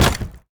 hit_wooden_crate_02.ogg